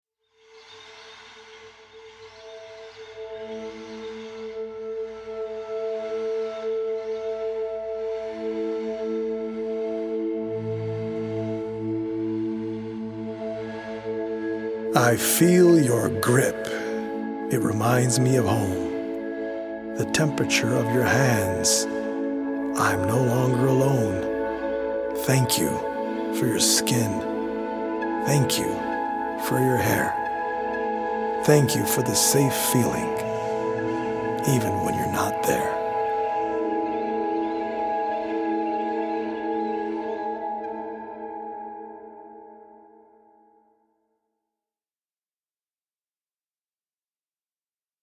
healing Solfeggio frequency music
EDM producer